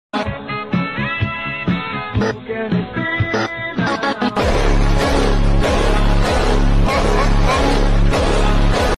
skull Trend Phonk Edit face sound effects free download